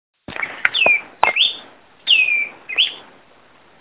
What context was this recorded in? Location or protected area: Parque Provincial Cruce Caballero Condition: Wild Certainty: Photographed, Recorded vocal